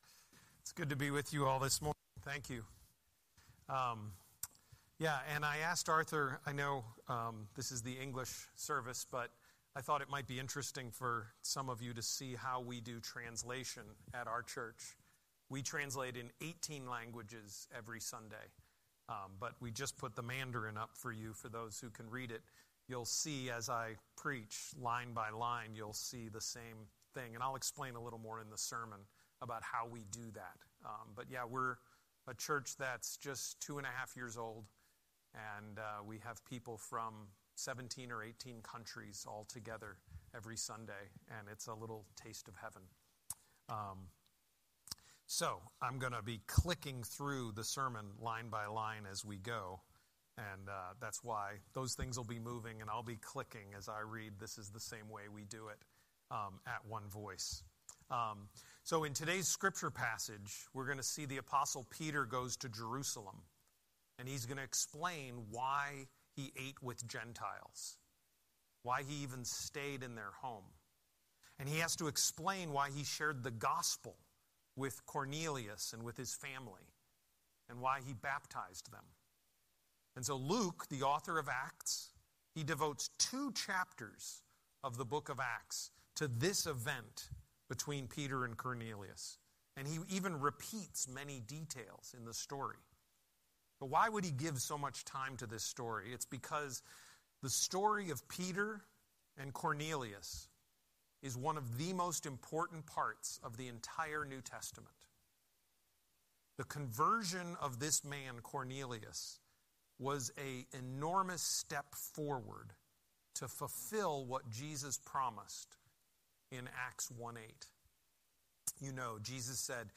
Scripture: Acts 11:1–18 Series: Sunday Sermon